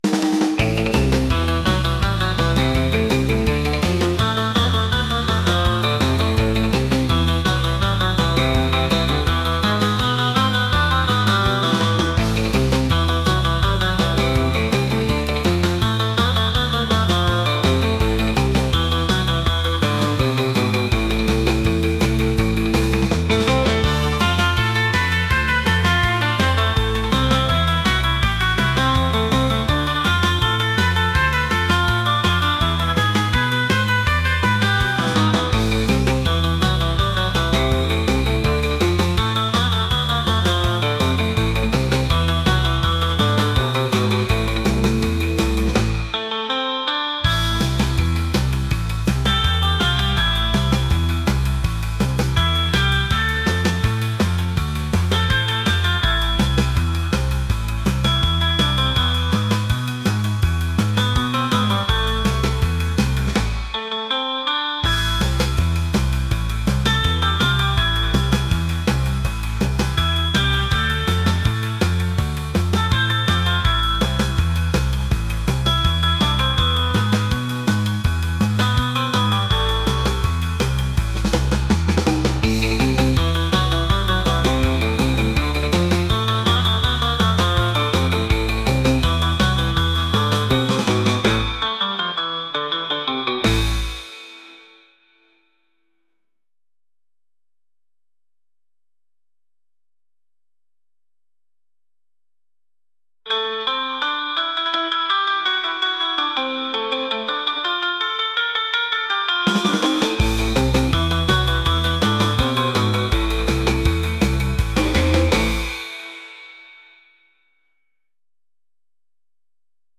rock | upbeat